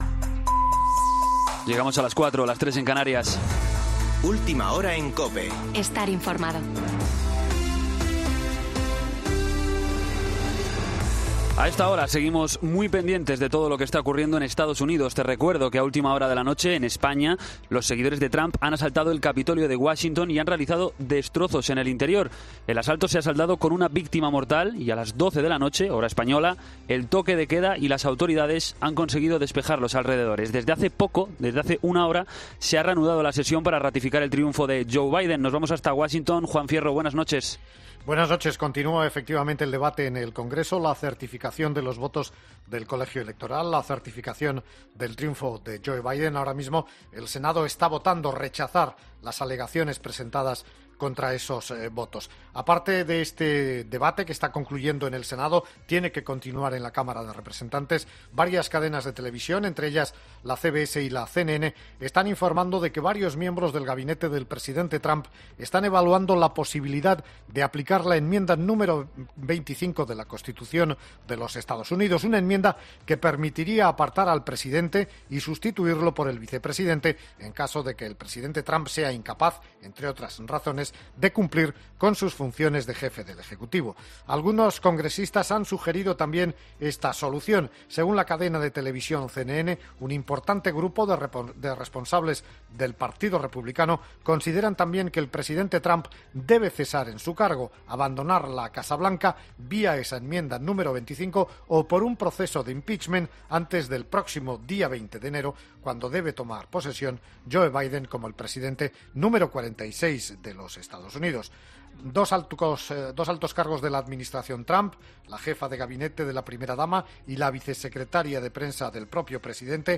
Boletín de noticias COPE del 7 de enero de 2020 a las 04.00 horas